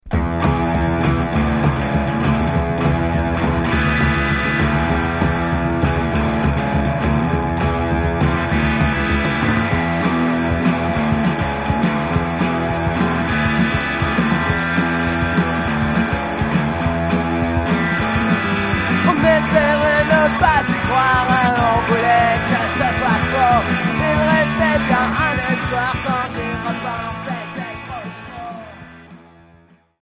Punk rock
chant